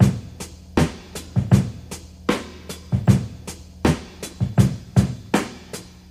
• 79 Bpm Tape Rock Drum Loop Sample C Key.wav
Free breakbeat sample - kick tuned to the C note. Loudest frequency: 1119Hz
79-bpm-tape-rock-drum-loop-sample-c-key-TiV.wav